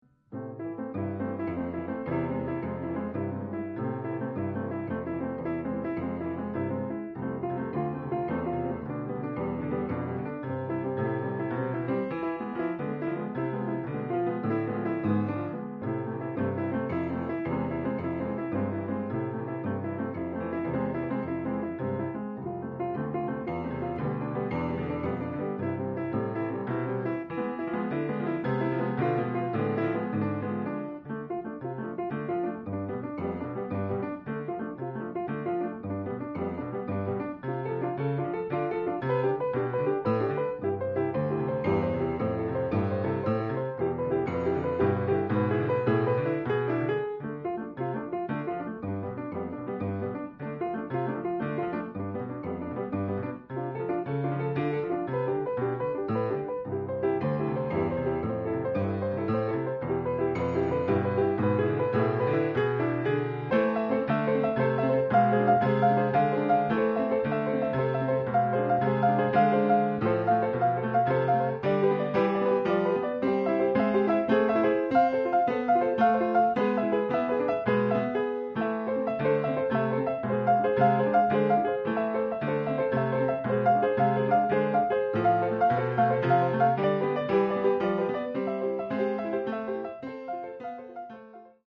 in do